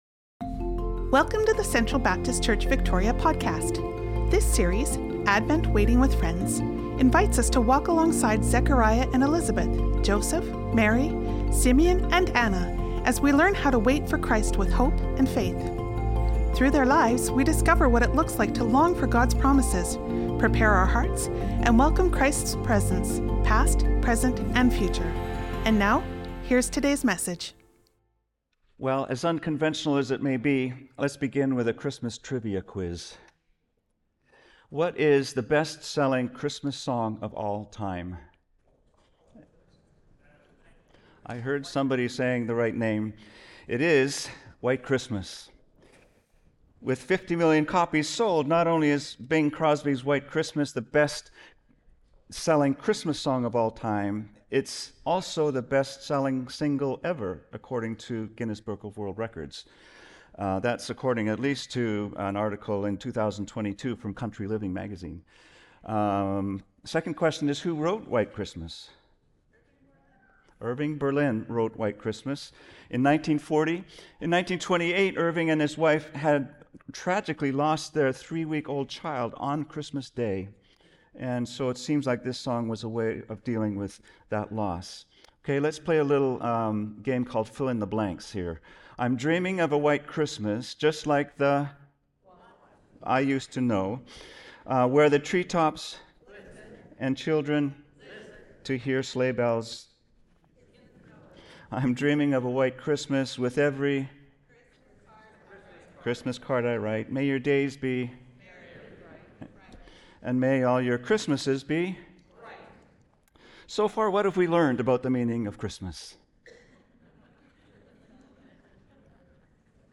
Sermons | Central Baptist Church